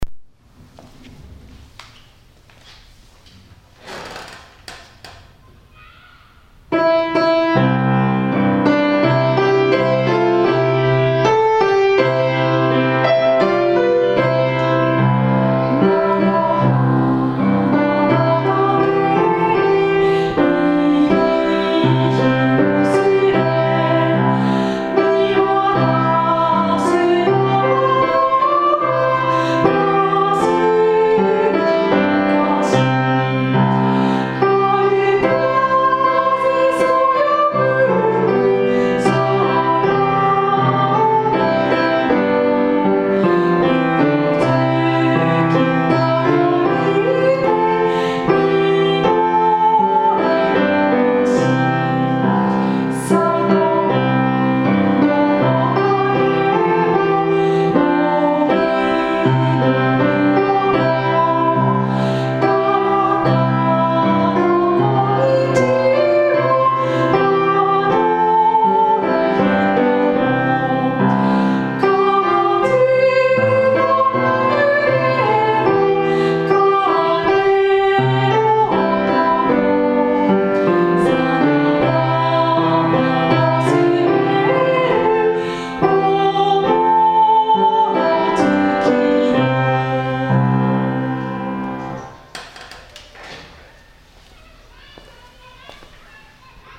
６年生の課題の歌「おぼろ月夜